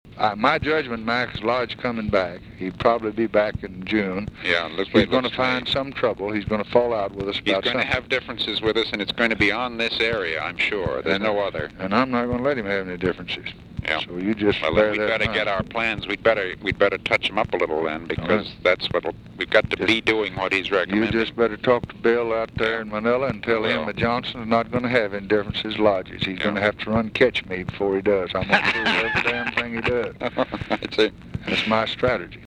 By mid-April, after Lodge’s New Hampshire win and lead in national polls, Johnson had all but turned over the short-term framing of Vietnam policy to Lodge, as he explained to National Security Advisor McGeorge Bundy.